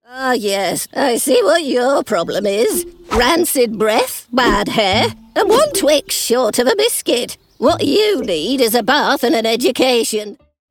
Her natural accent is neutral RP but she can also deliver convincing West Country, General Northern and Estuary accents.
standard british | natural
ANIMATION 🎬
elderly